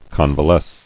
[con·va·lesce]